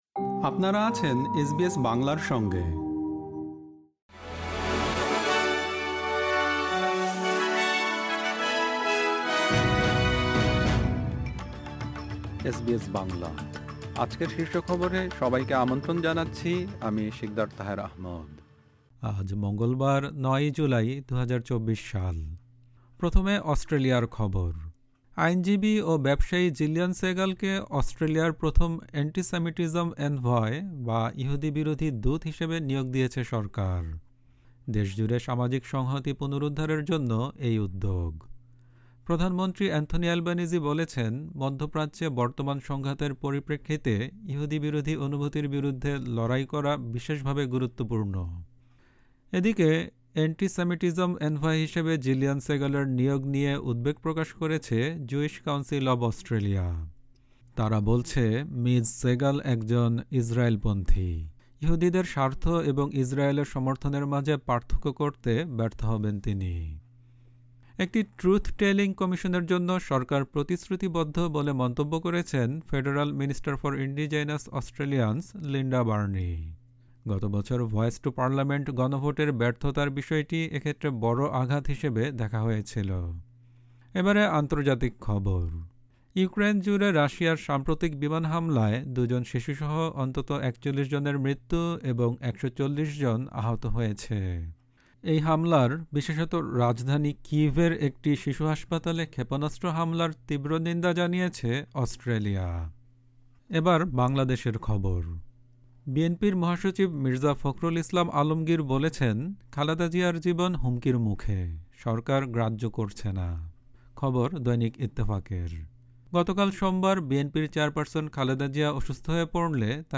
এসবিএস বাংলা শীর্ষ খবর: ৯ জুলাই, ২০২৪